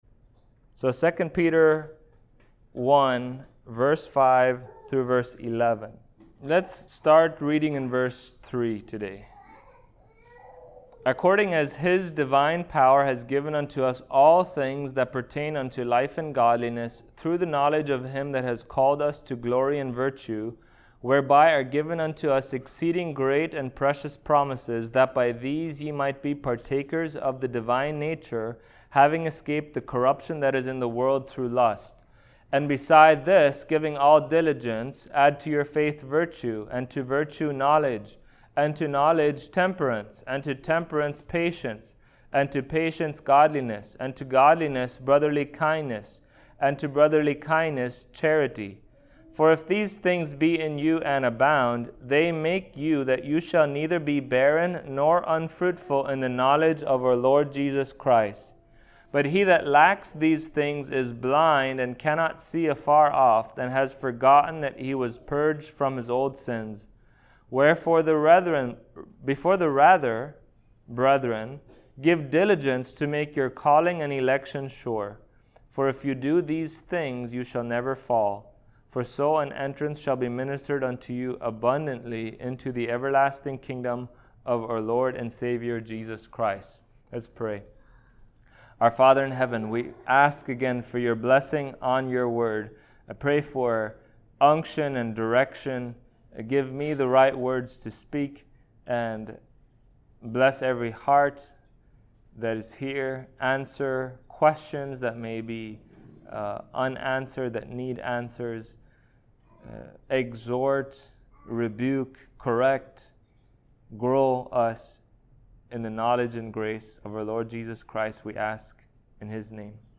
2 Peter 1:5-11 Service Type: Sunday Morning Topics